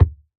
Minecraft Version Minecraft Version 1.21.5 Latest Release | Latest Snapshot 1.21.5 / assets / minecraft / sounds / block / packed_mud / step3.ogg Compare With Compare With Latest Release | Latest Snapshot